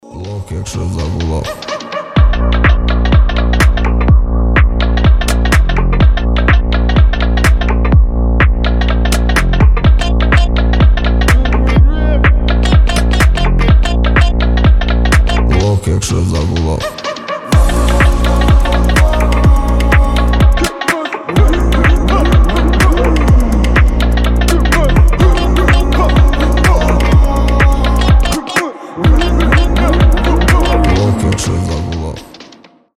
trap , басы